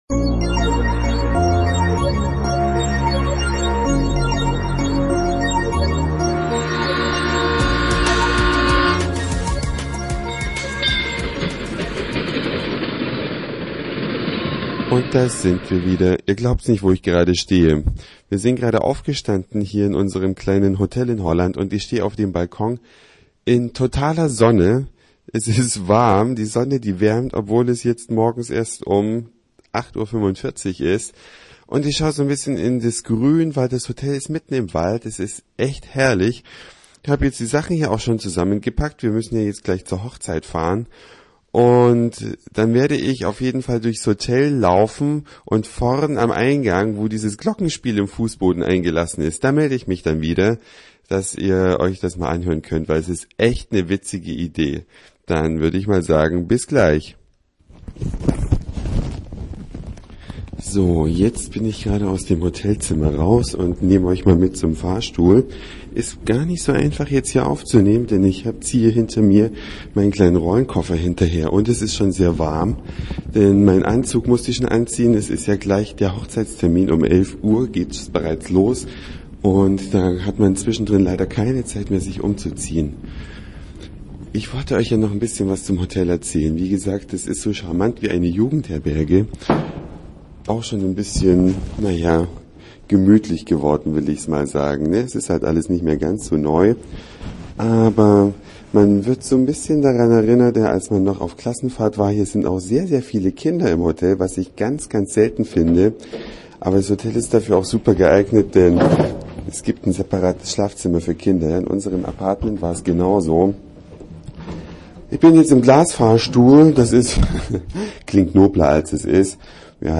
Ein Glockenspiel im Eingangsbereich des Hotels in
Enschede(Holland) klingt witzig, tolle Idee.